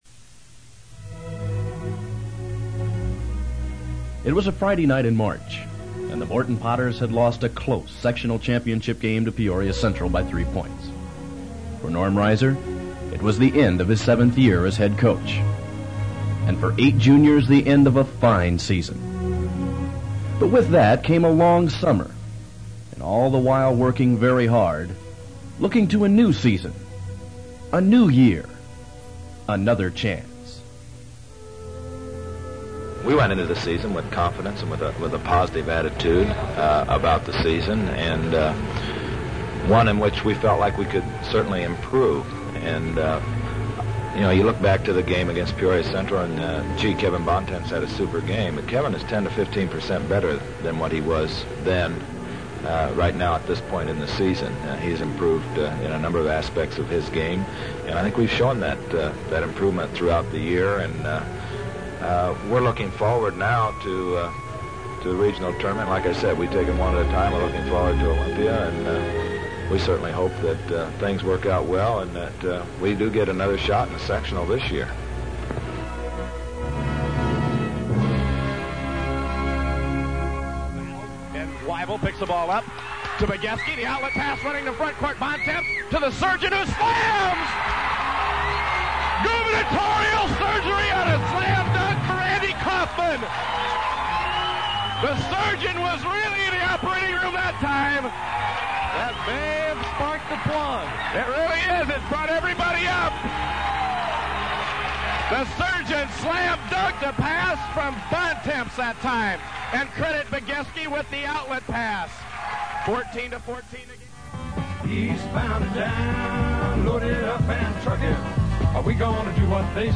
Recently while we were cleaning out some old boxes I came across a cassette tape of a show that WTAZ ran at the end of our 1978-79 basketball season. If I recall correctly, it was broadcast after the regular season and before the tournaments.